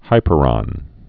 (hīpə-rŏn)